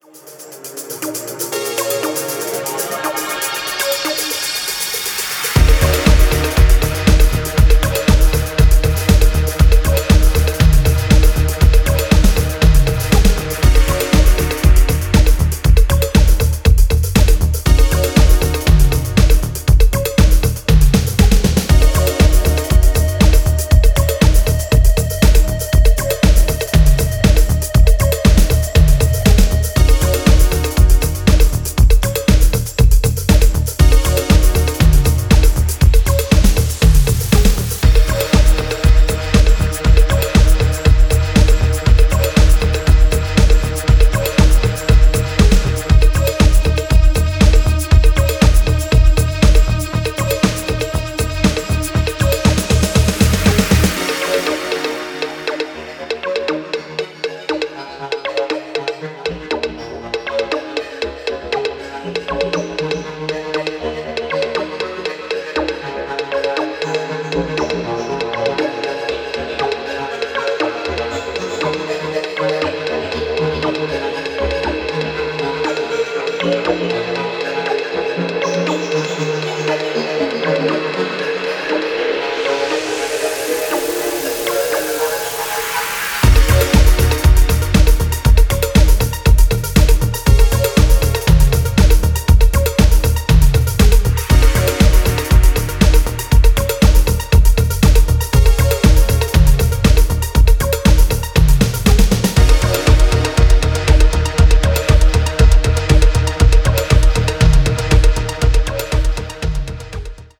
Disco Electronix